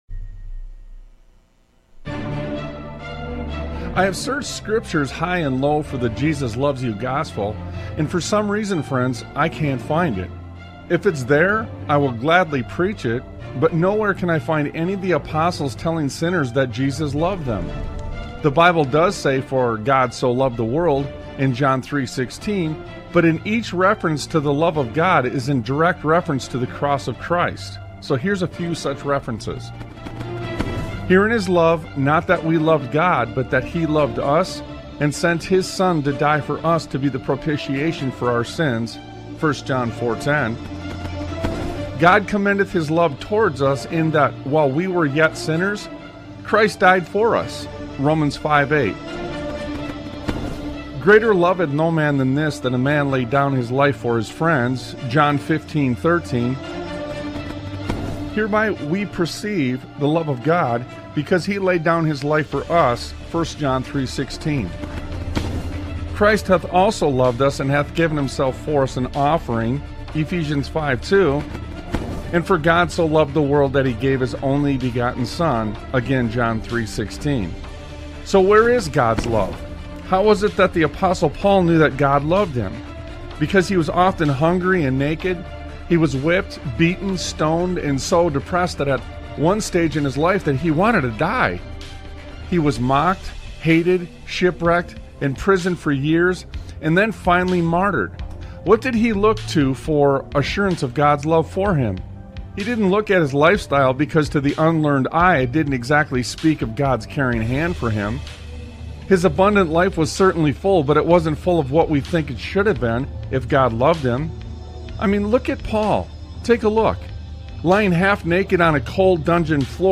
Talk Show Episode, Audio Podcast, Sons of Liberty Radio and A Continuation on , show guests , about A Continuation,The Cross,the Constitution,the Crisis of Character,A Call to the Old Paths,Old Paths vs. Modernity,The Passion of the Christ, categorized as Education,History,Military,News,Politics & Government,Religion,Christianity,Society and Culture,Theory & Conspiracy